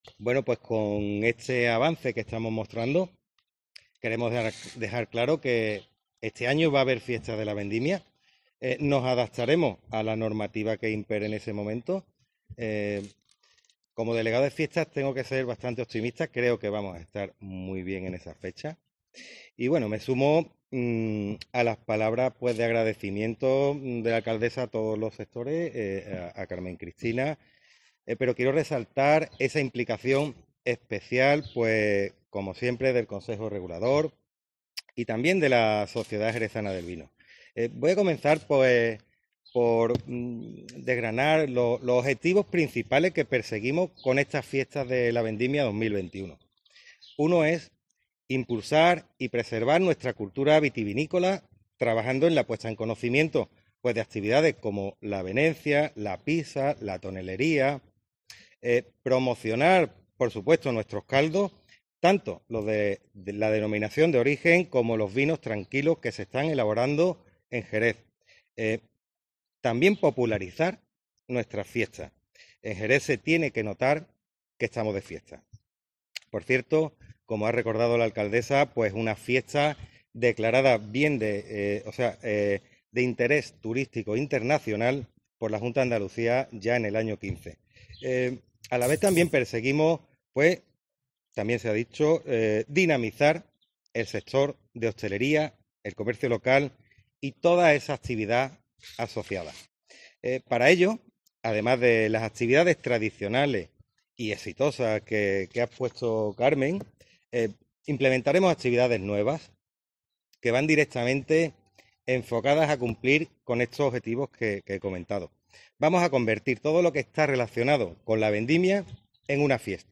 Conciertos, atracciones, actividades infantiles y familiares, engalanamiento, catas maridadas, concurso de tapass, barras en veladores, escenarios, exhibiciones y consursos de oficios como los de venenciador, prensa o tonelería son parte de cuanto espera para esos días a la ciudad en el ánimo claro que expresó en esta presentación el concejal delegado de Fiestas del Ayuntanmiento de Jerez, Rubén Pérez: "Vamos a convertir en fiesta todo lo relacionado con la vendimia".